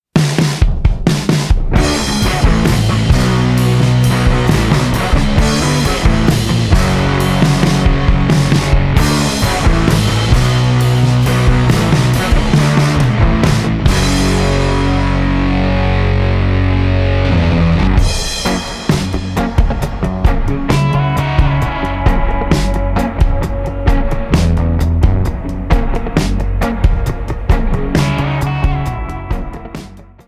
Voix, Guitares
Batterie